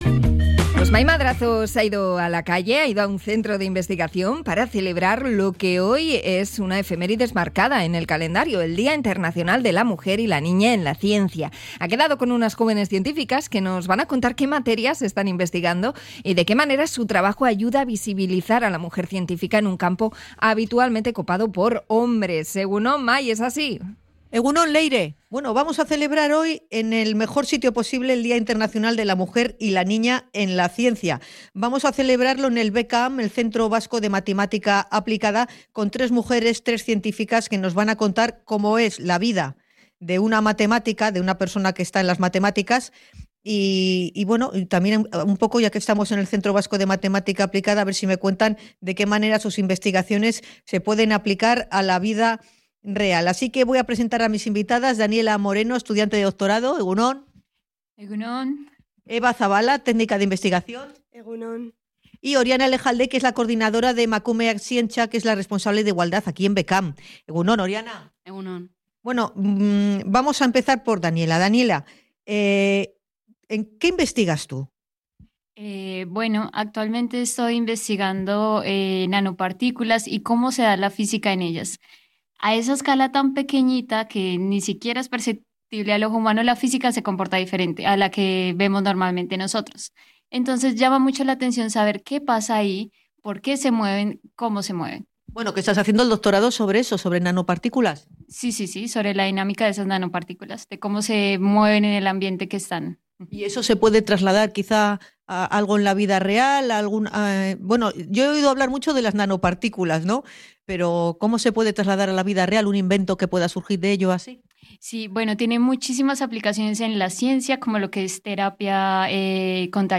Hablamos con jóvenes investigadoras en el Centro vasco de Matemática Aplicada